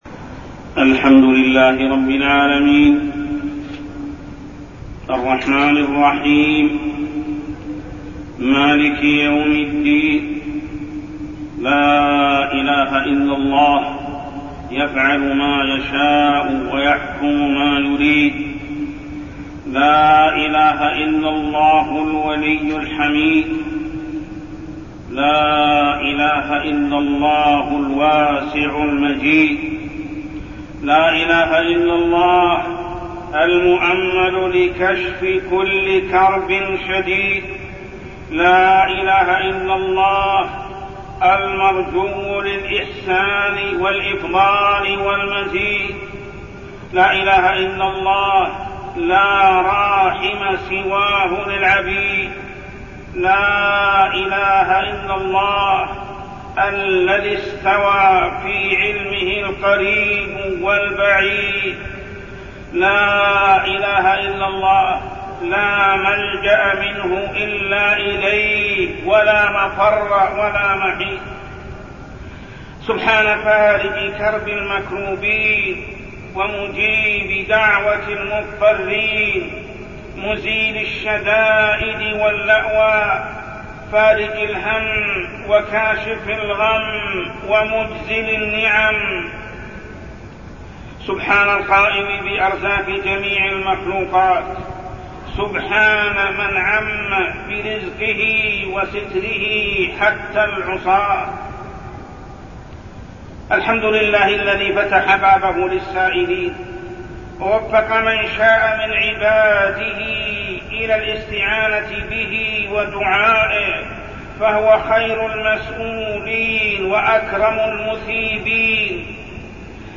تاريخ النشر ١٤ رجب ١٤٠٩ هـ المكان: المسجد الحرام الشيخ: محمد بن عبد الله السبيل محمد بن عبد الله السبيل منع الزكاة من موانع القطر